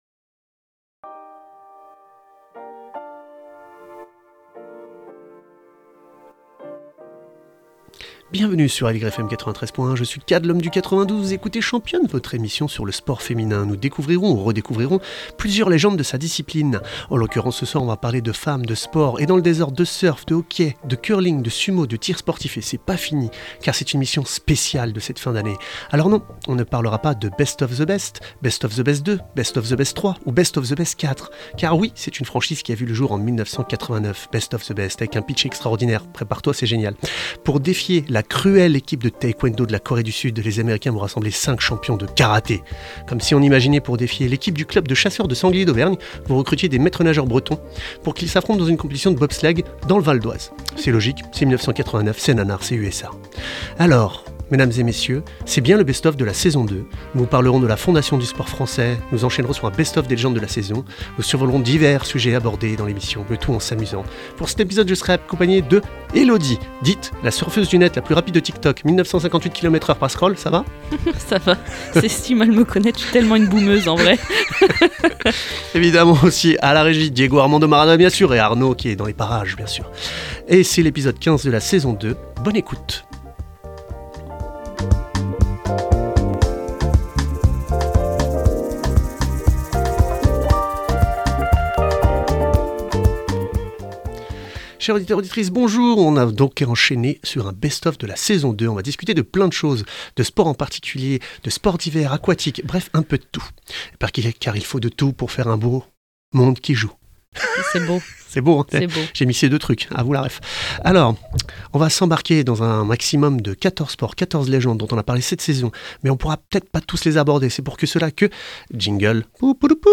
Qu’elles soient amatrices, semi-pros ou pros, nous échangeons avec des invitées inspirantes, qui partagent leurs visions et leurs expériences.